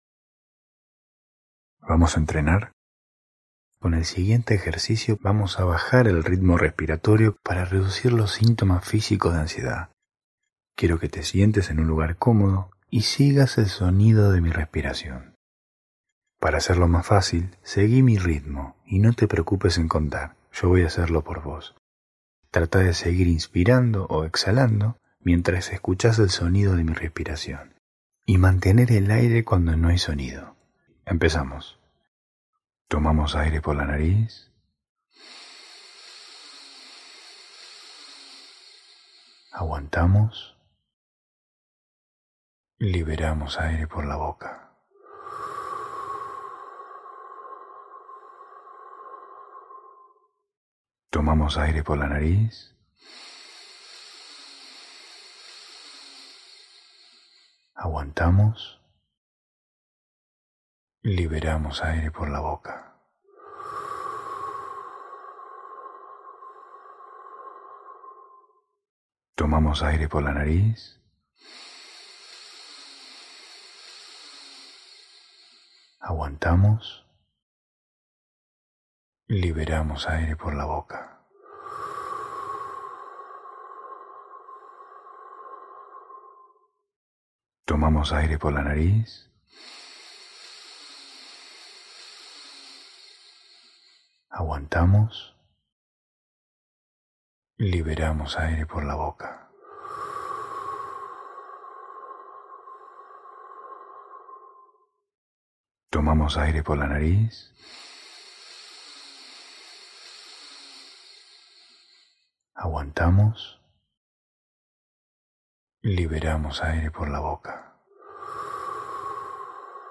Sesión de respiración guiada para disminuir el ritmo respiratorio y reducir los síntomas de ansiedad